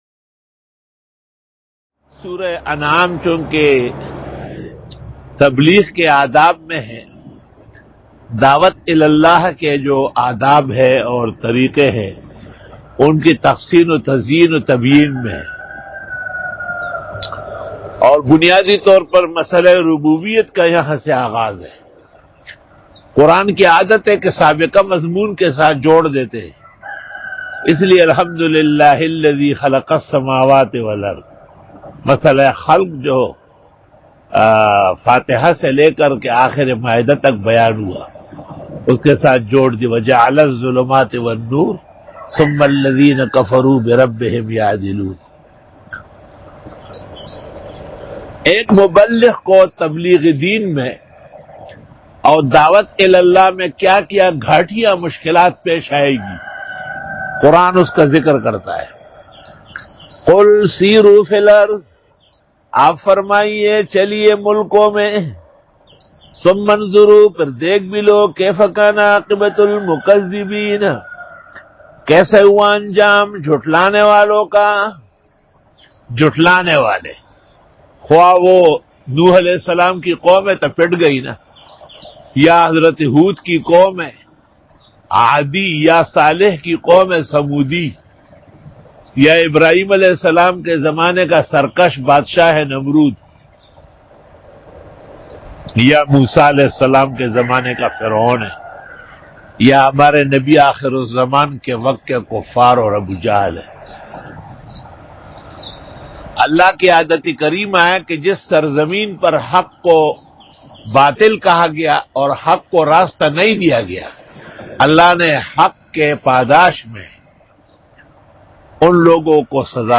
Dora-e-Tafseer 2020 Bayan